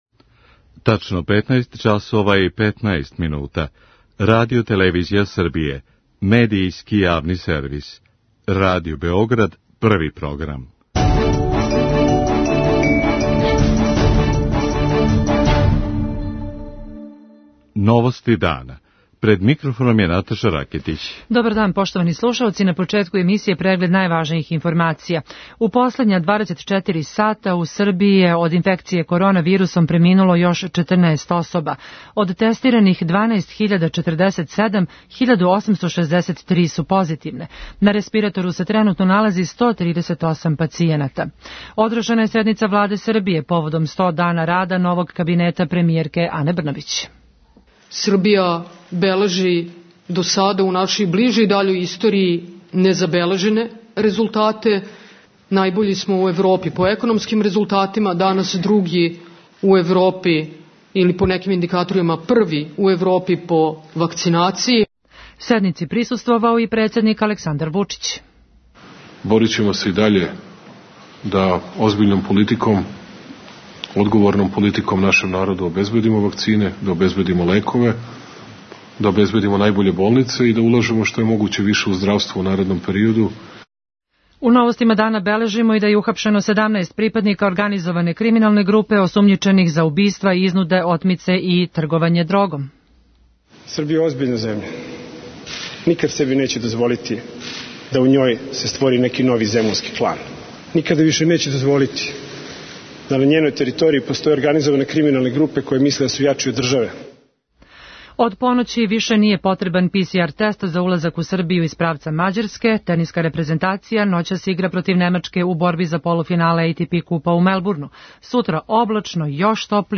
Nakon sednice na Prvom programu prenosimo obrćanje premijerke i predsednika.